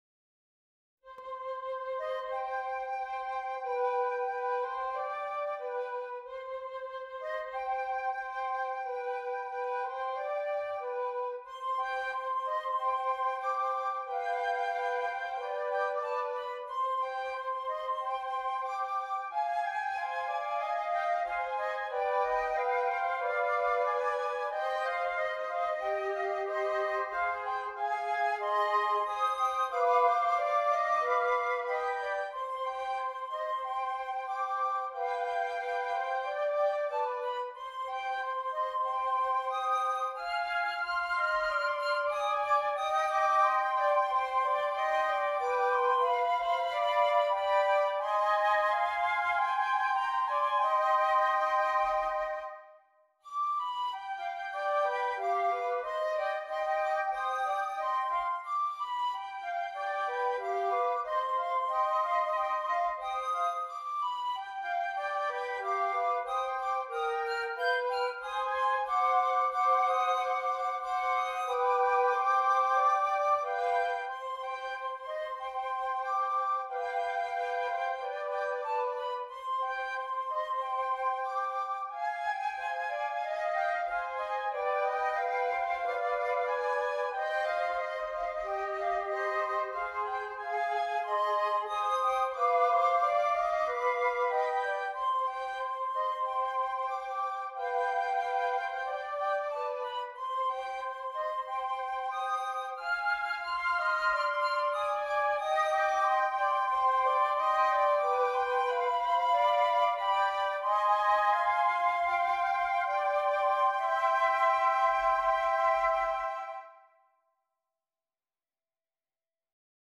3 Flutes